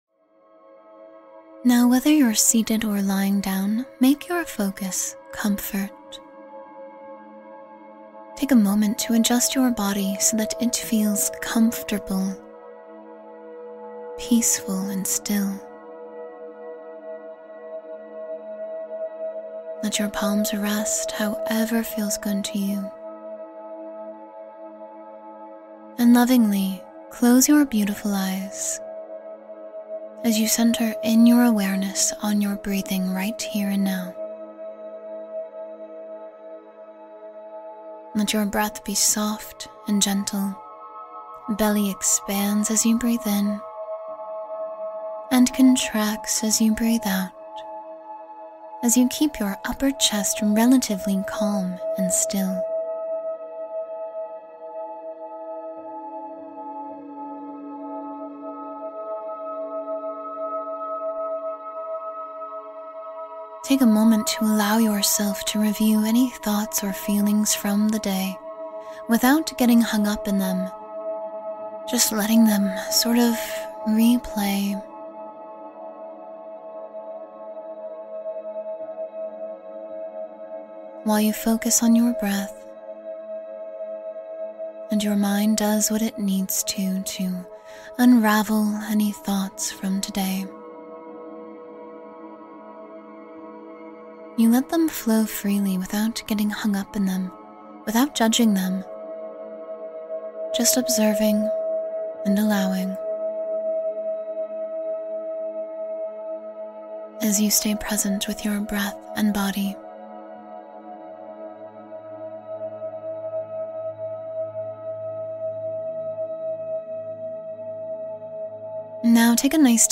Sleep Deeply in 10 Minutes — Guided Meditation for Relaxation